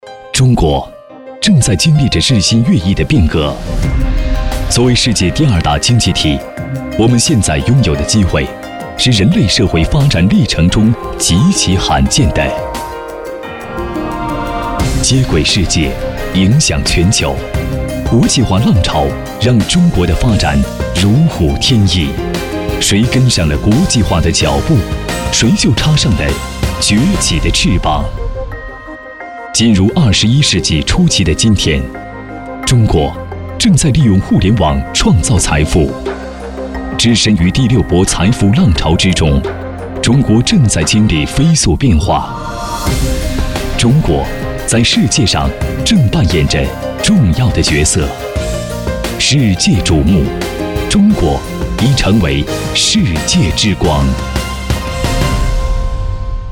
企业男92号（浑厚激情）
年轻时尚 企业宣传配音
年轻时尚男音，偏浑厚。擅自宣传片，微电影旁白，讲述，专题等题材。作品：互联网科技。